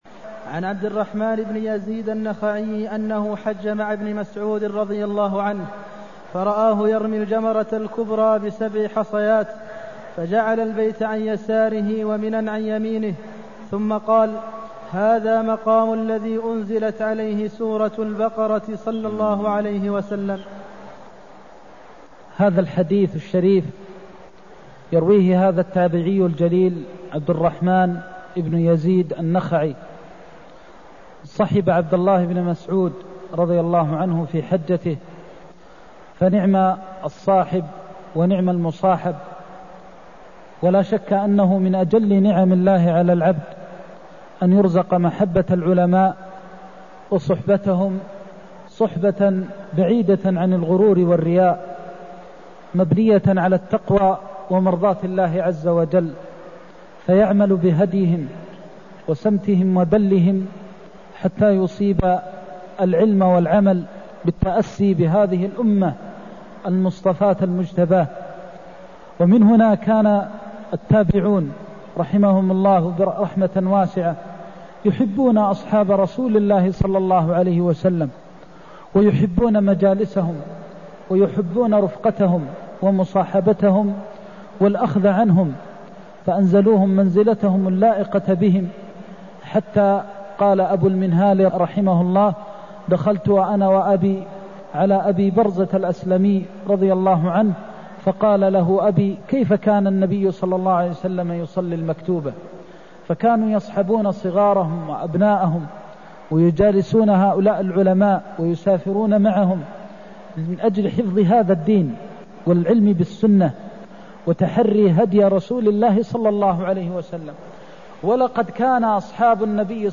المكان: المسجد النبوي الشيخ: فضيلة الشيخ د. محمد بن محمد المختار فضيلة الشيخ د. محمد بن محمد المختار رمي الجمار من بطن الوادي (235)